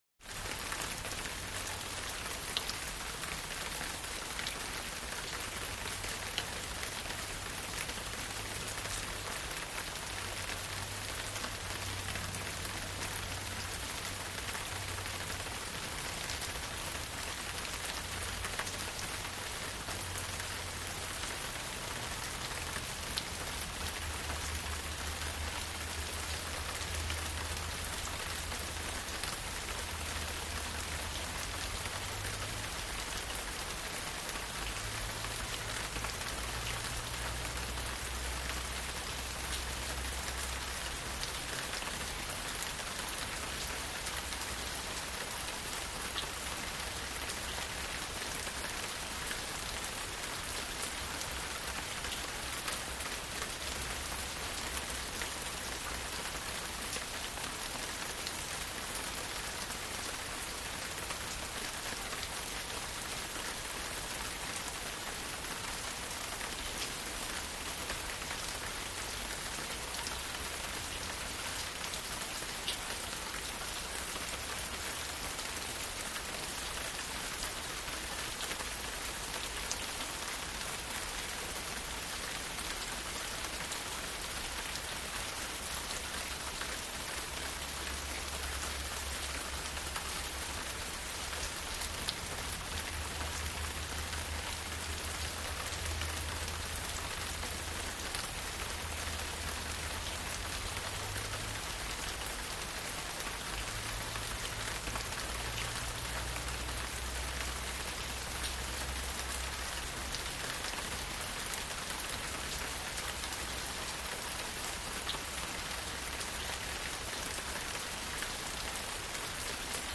Rain; Medium, On Porch And Plants, With Distant Traffic.